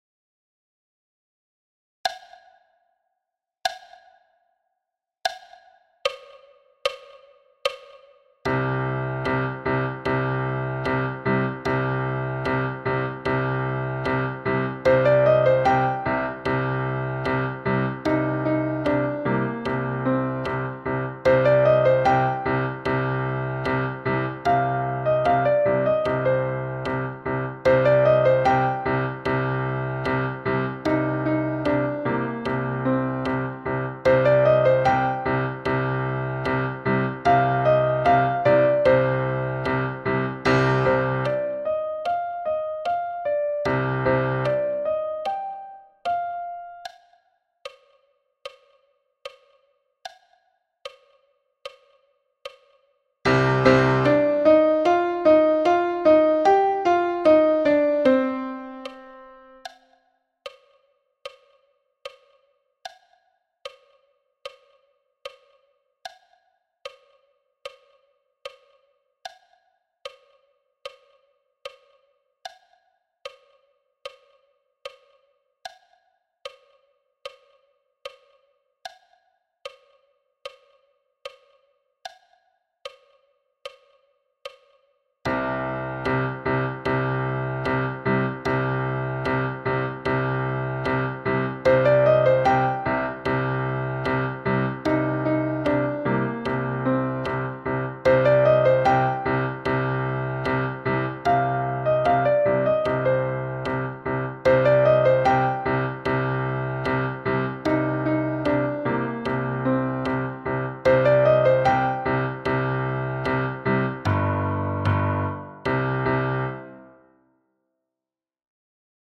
Parlons timbales – Piano – block à 75 bpm